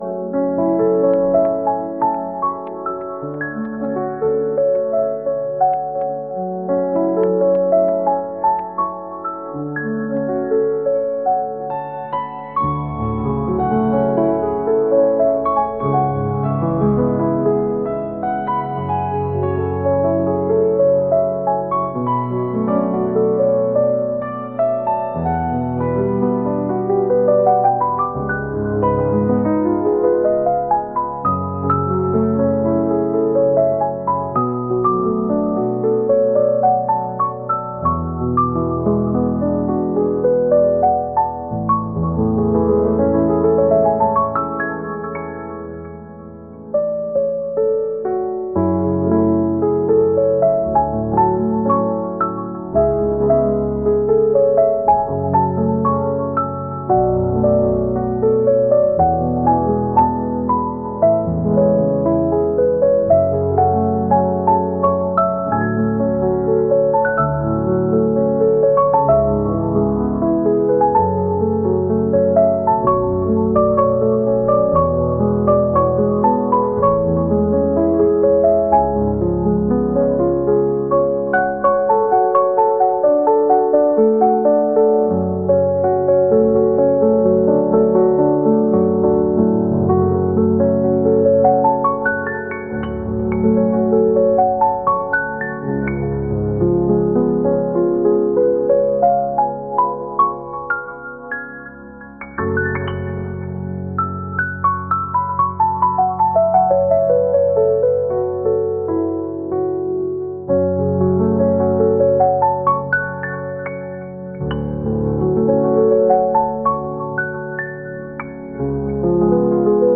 満天の星空を眺めているようなピアノ曲です。